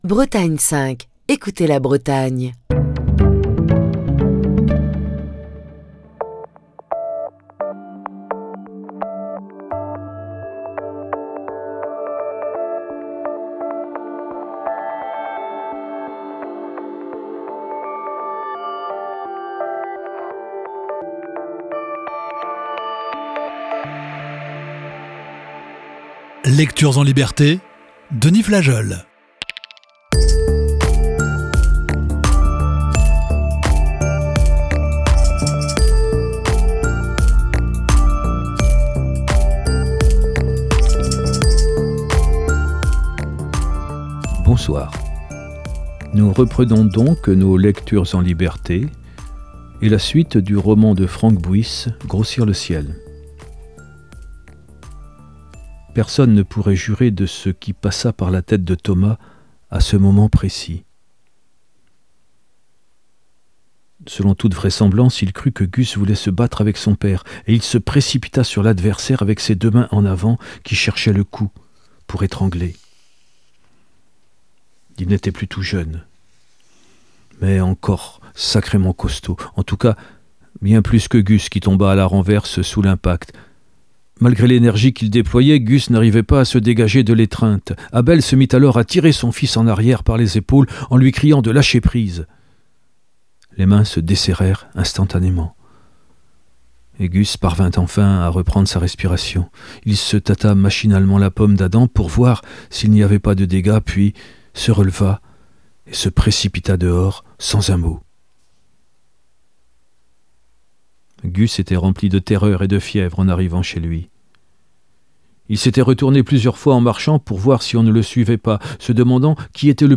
Émission du 10 octobre 2023.